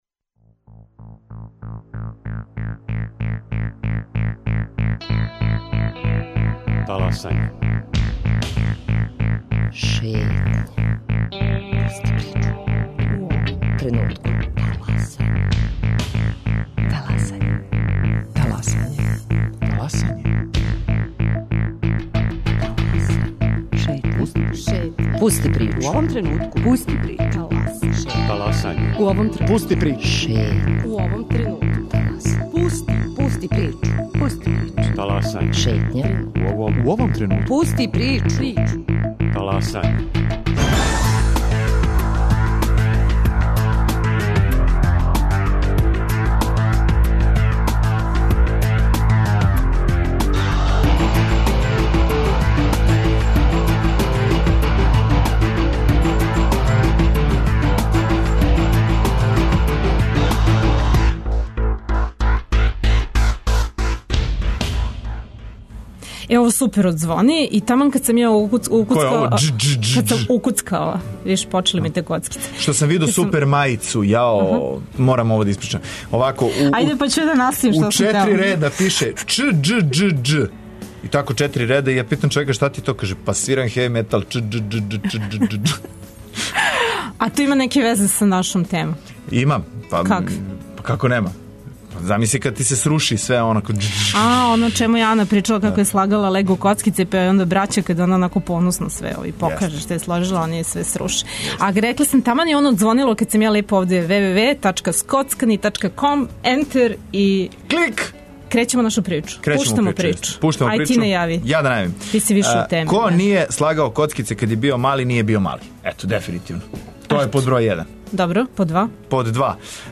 На пример, треће лего изложбе у Београду. Не морате да се скоцкате, ми ћемо скоцкане довести у студио.